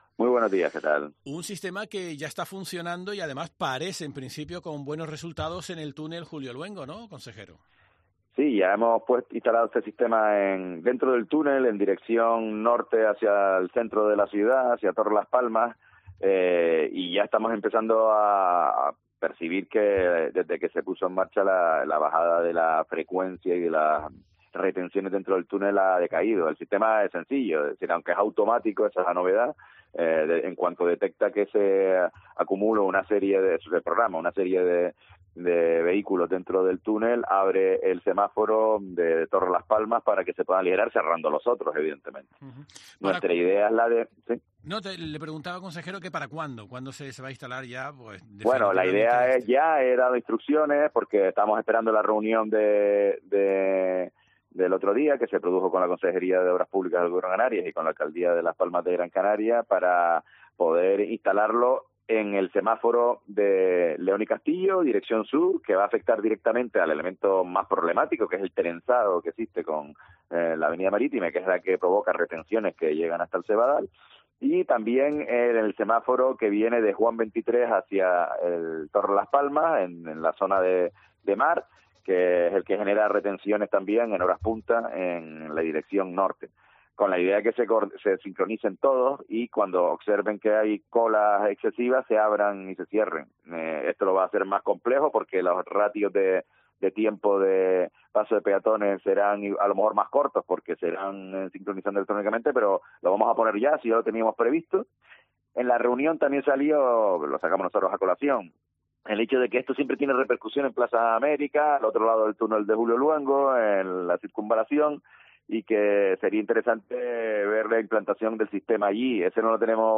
Augusto Hidalgo, consejero de Obras Públicas, Infraestructuras, Arquitectura y Vivienda